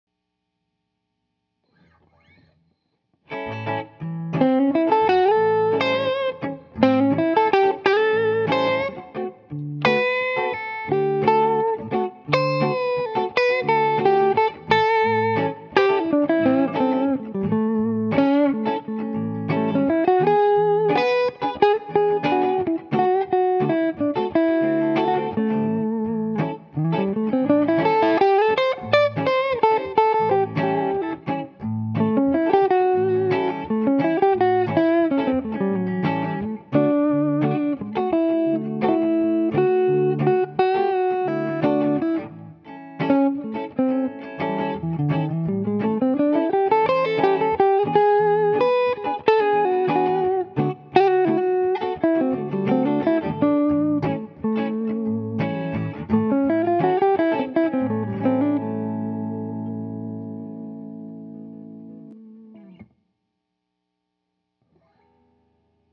This set has a sweet and fat low end that is strong and punchy with just the perfect balance of lower mids.  The top end is smooth and has a great presence that balances great with the solid mid range.